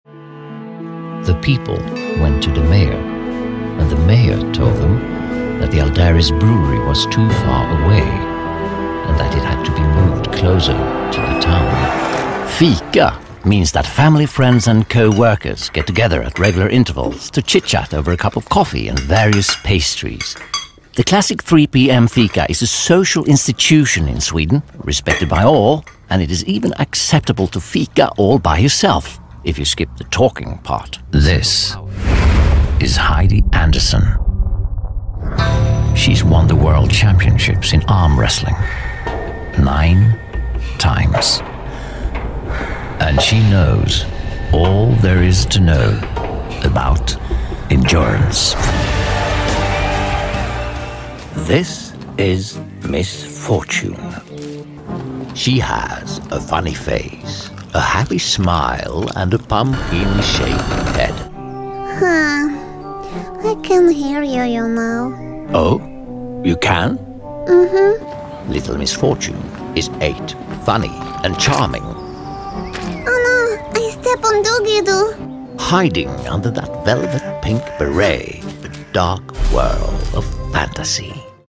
Voicereel
Attore svedese, ottima conoscenza della lingua inglese,Residente a Stoccolma